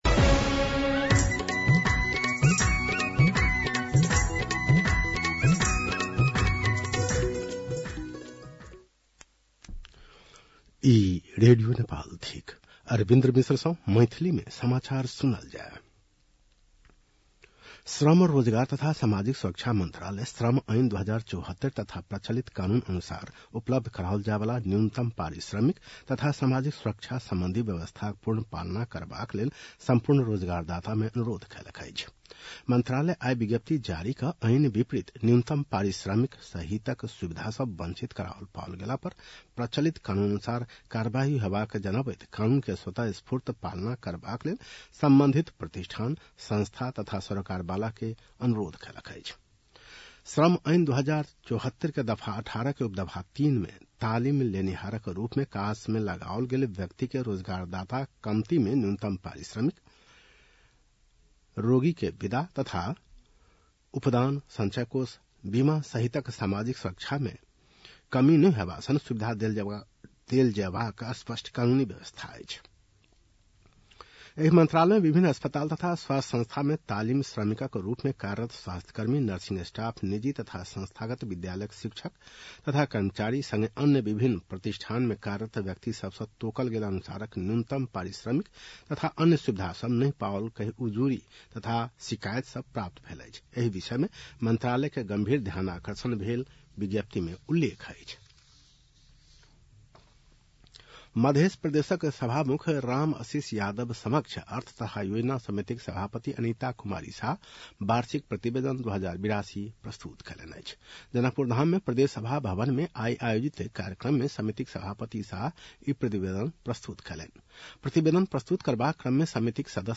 मैथिली भाषामा समाचार : २३ चैत , २०८२
6.-pm-maithali-news-.mp3